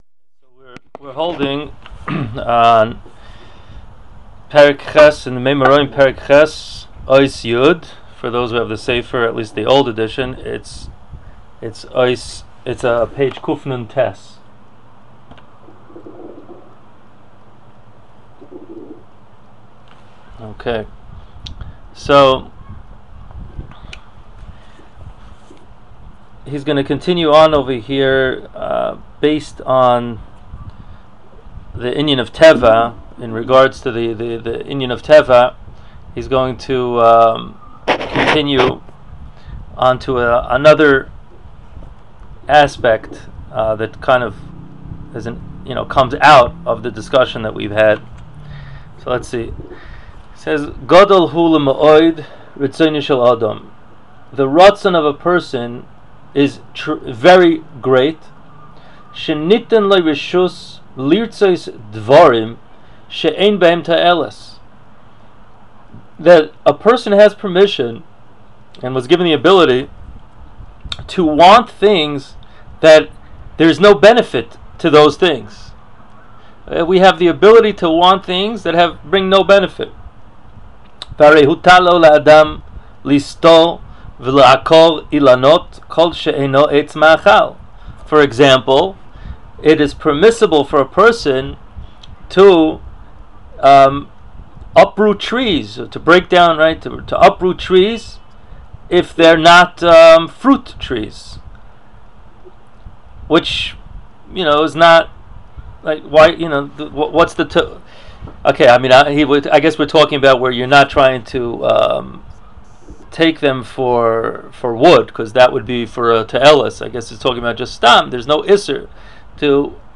Here is the text if you would like to follow with the shiur: